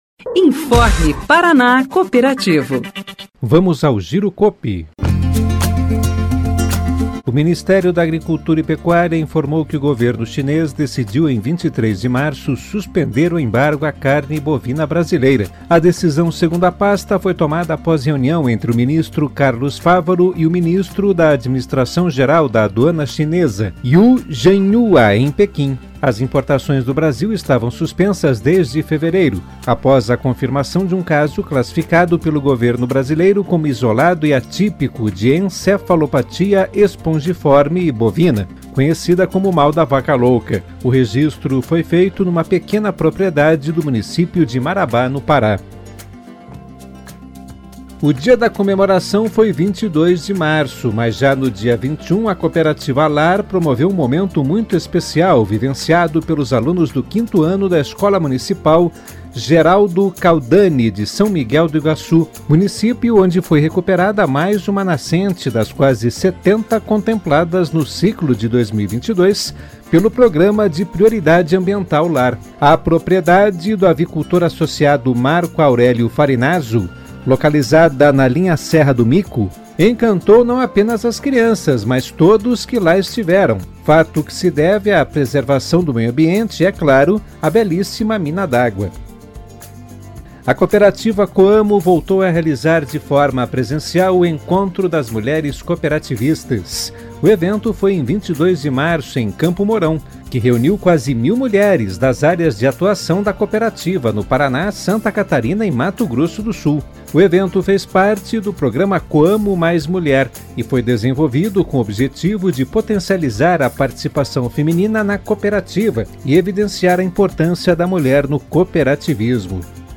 Notícias Rádio Paraná Cooperativo